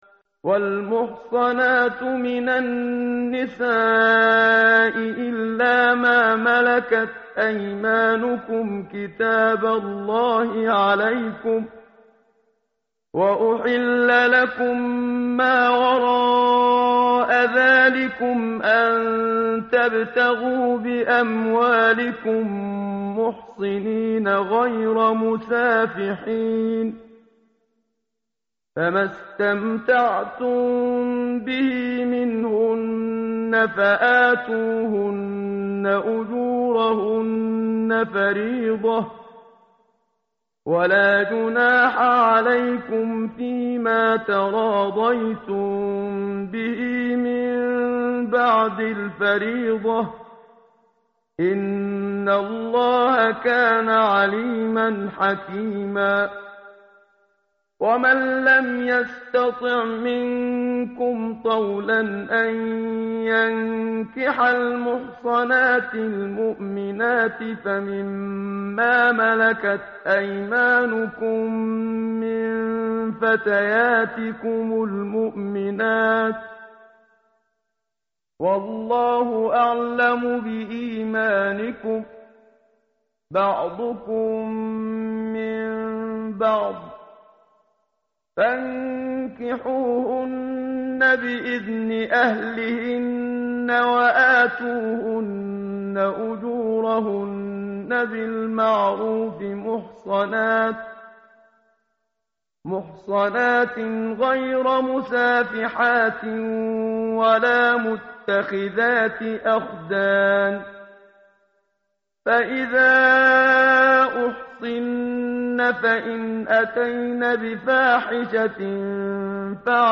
متن قرآن همراه باتلاوت قرآن و ترجمه
tartil_menshavi_page_082.mp3